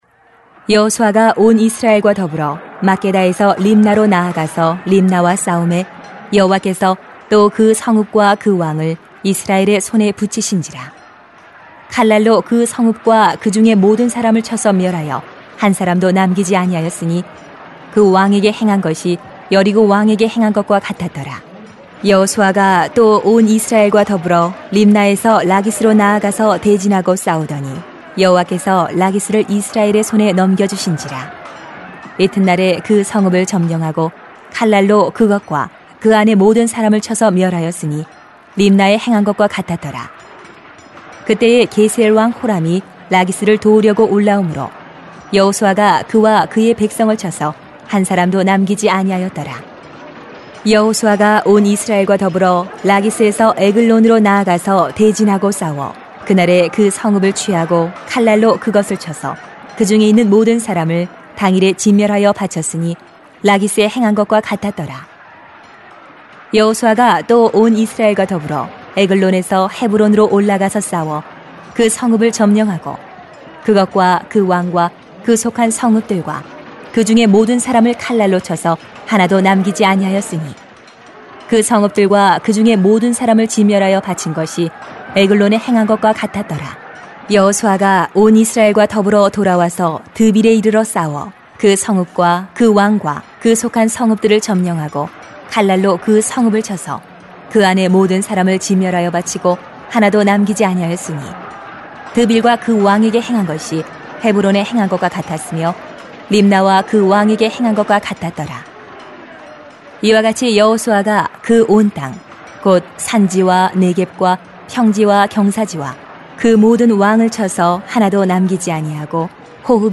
[수 10:29-43] 우리를 위해 일하시는 하나님을 경험합시다 > 새벽기도회 | 전주제자교회